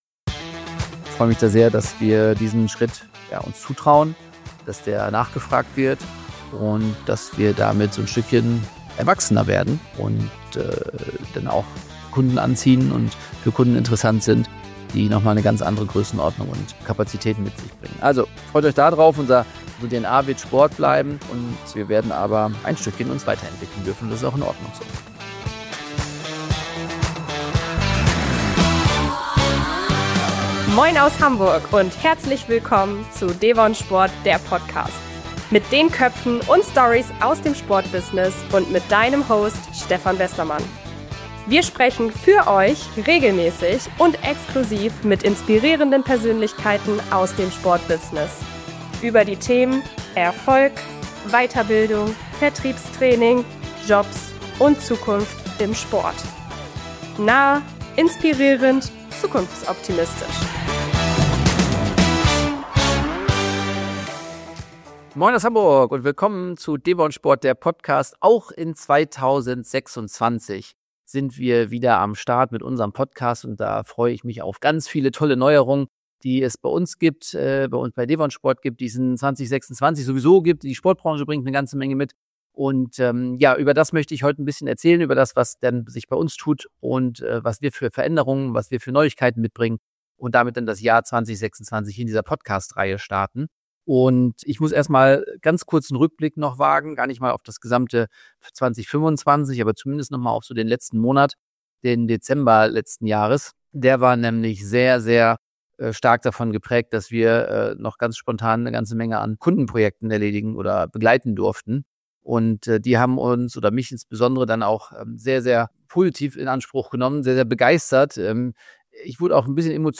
In dieser Solo-Folge nehme ich dich mit hinter die Kulissen und zeige, wohin die R… … continue reading